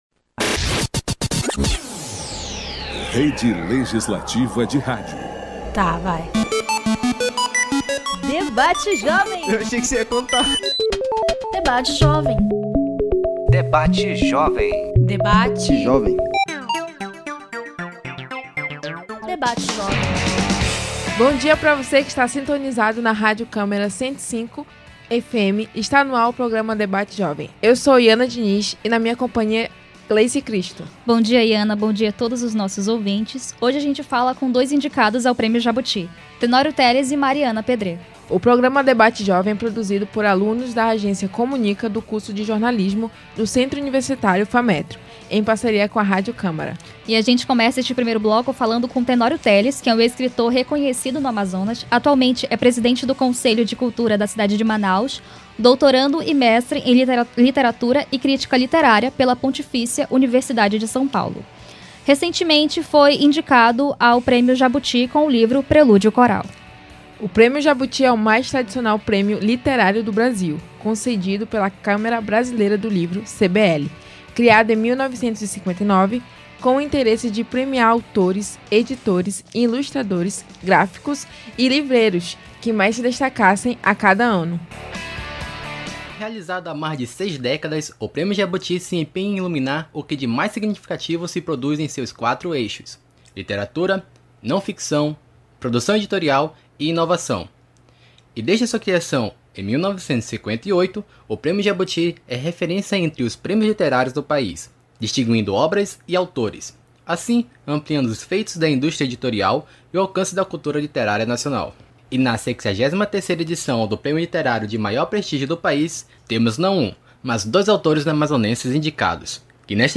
Essa edição do programa Debate Jovem é dedicada ao prêmio Jabuti. Conversamos com dois escritores amazonenses, que estão concorrendo ao prêmio.